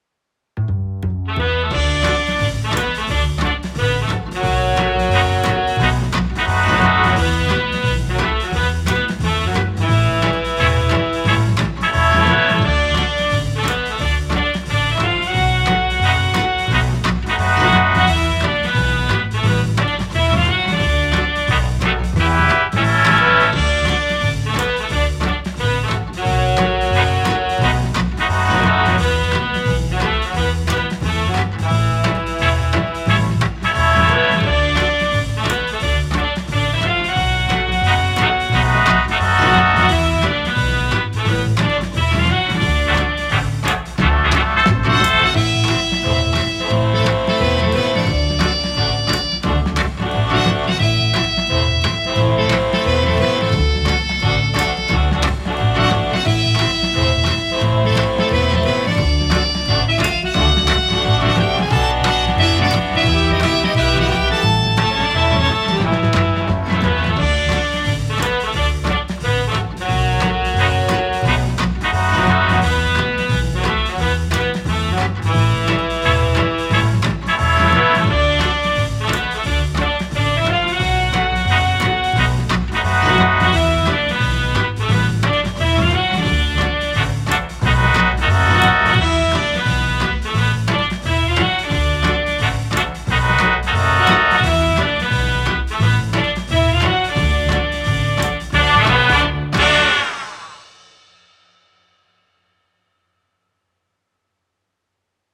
ループ可 ジャズ ギター トランペット 明るい